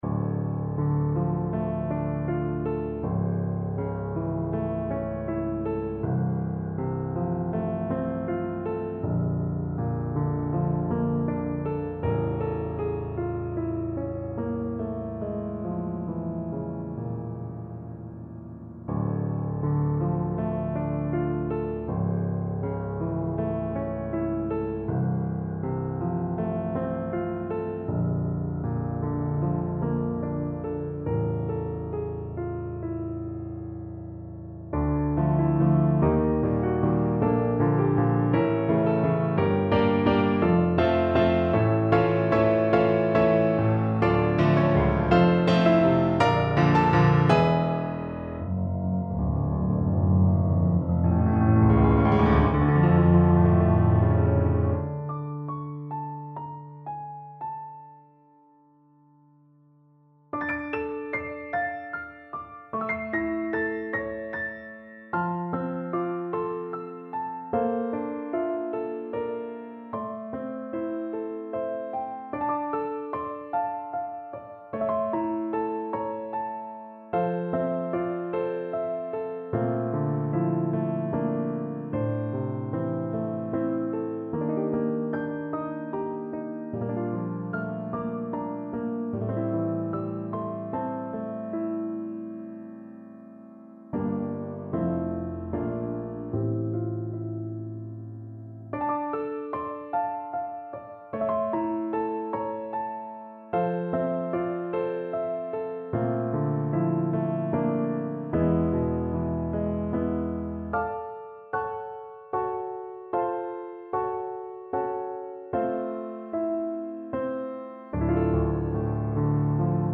Nocturne in D Minor - Piano Music, Solo Keyboard - Young Composers Music Forum